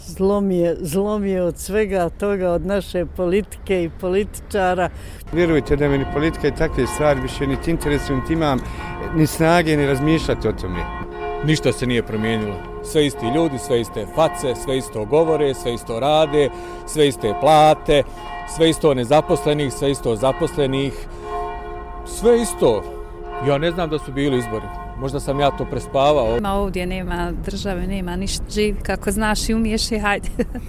Građani o tome zašto nema vlasti